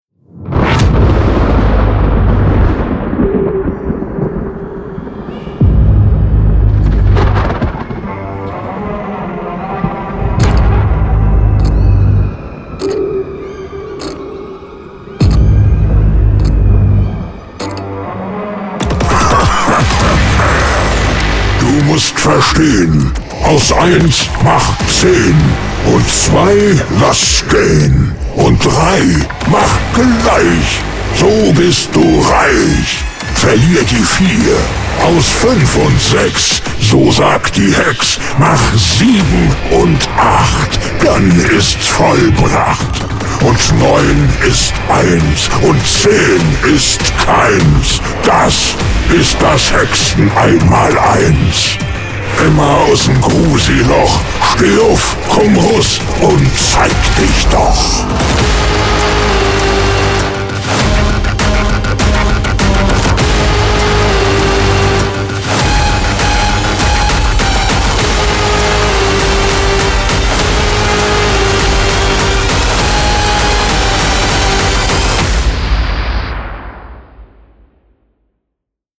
Die Lahrer Narrenzunft wünschte sich ein neues Intro – Jingle für Ihren Hexentanz.
Intro zum Hexentanz